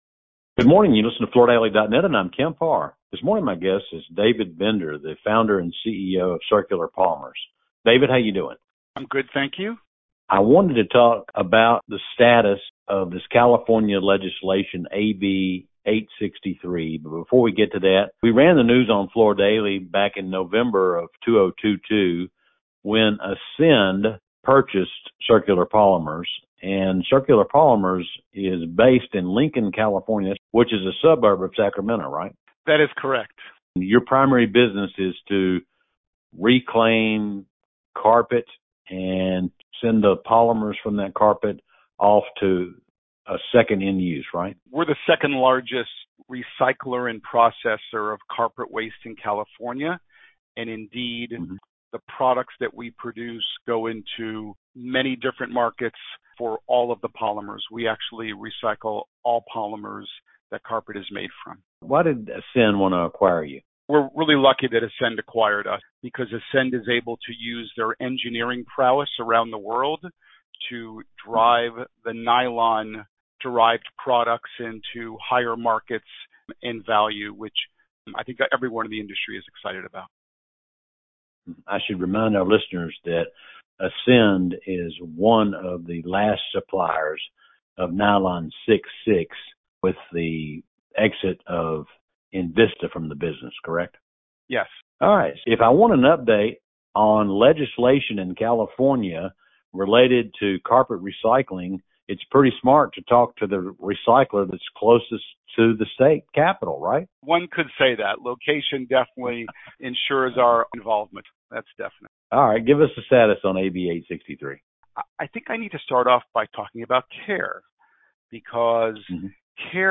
Listen to the interview to learn what’s in the bill, and what’s not in the bill.